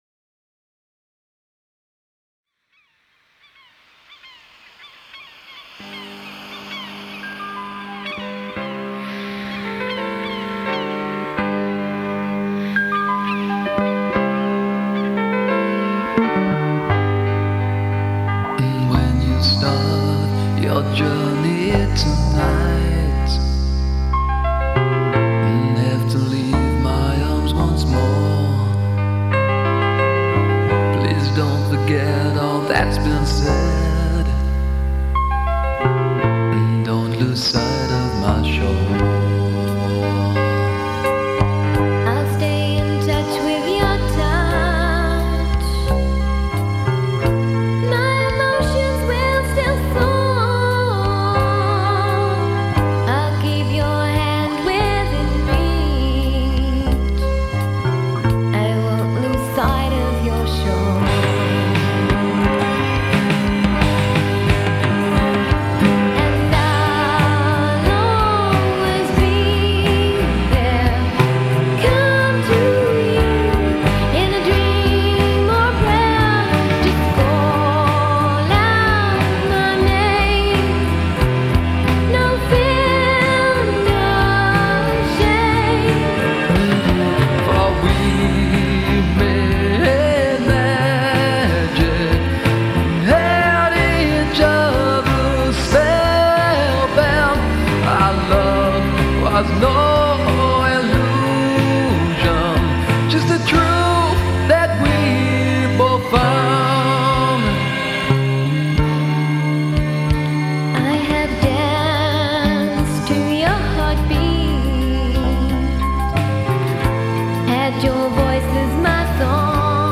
I have a recording studio”  And that’s what happened.
as a duet